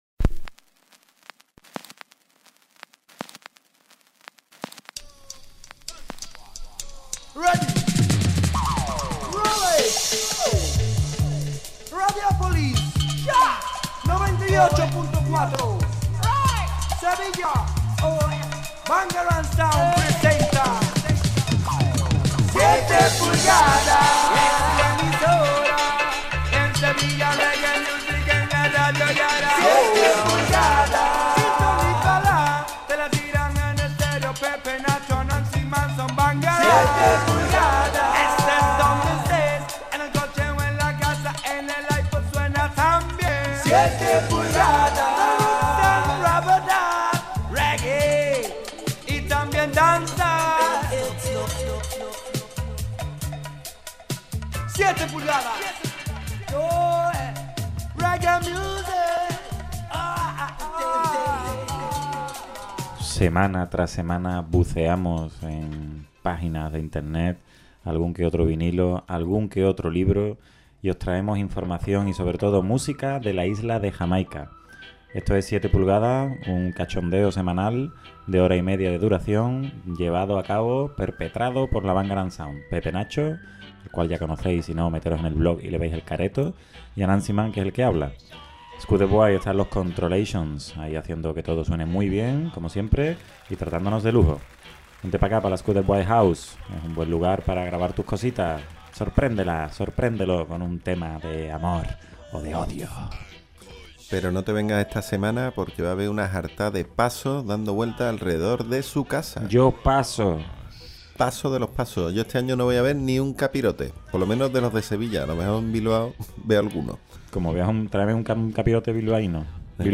Ya podéis escuchar el programa 282 de 7PULGADAS, emitido este pasado viernes 11 de Abril. Presentado y dirigido por la Bangarang Sound y grabado en la Skuderbwoy House.